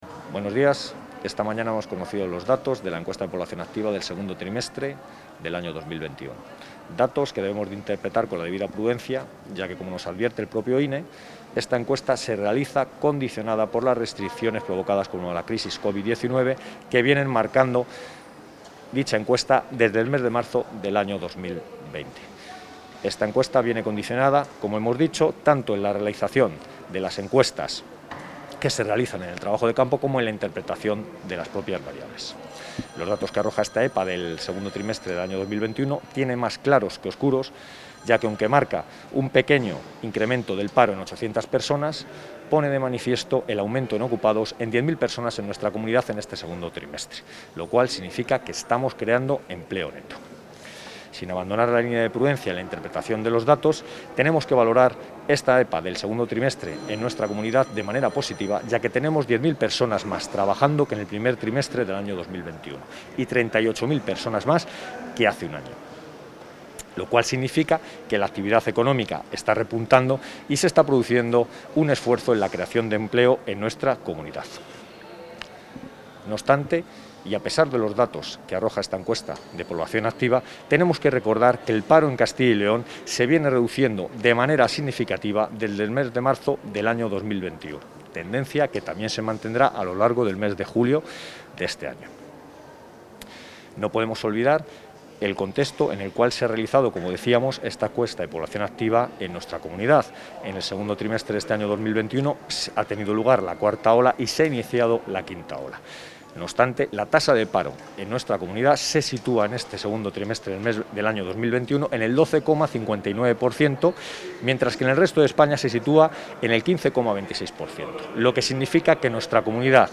Valoración del viceconsejero.